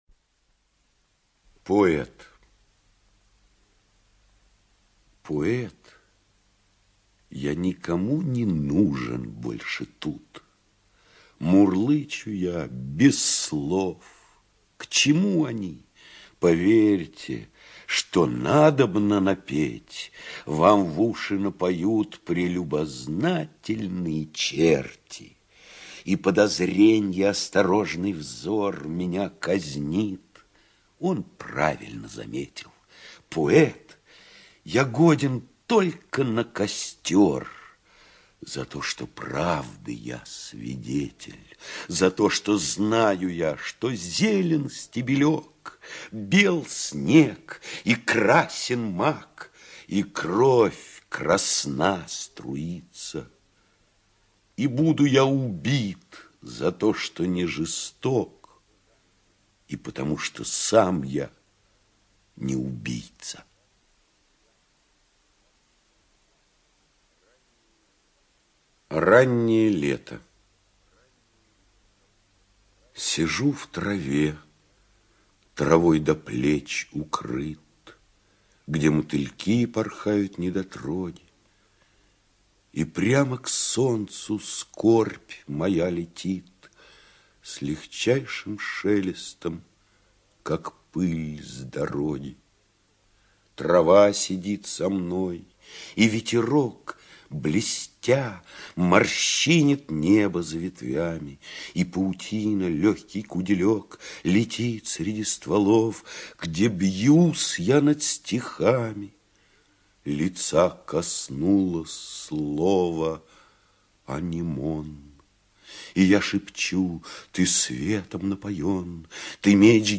ЧитаетКваша И.
ЖанрПоэзия